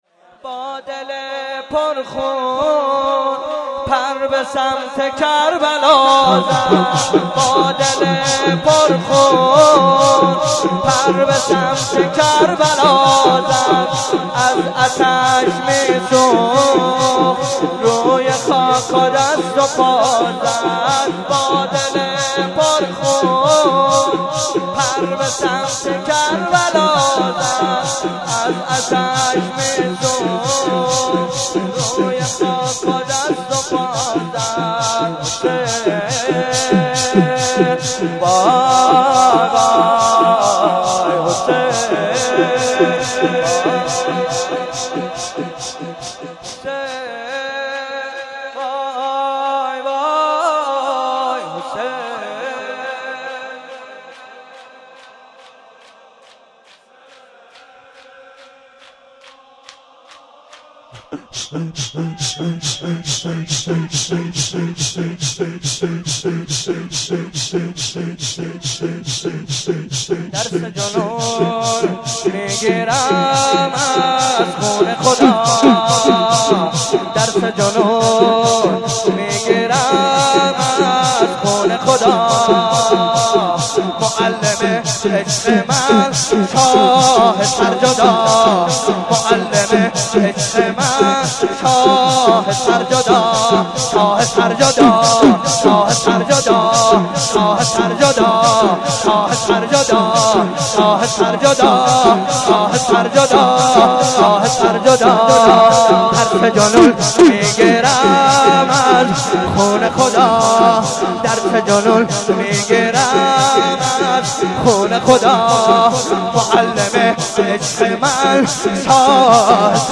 مداح
قالب : شور
05.sineh zani.mp3